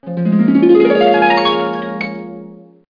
1 channel
harpup10.mp3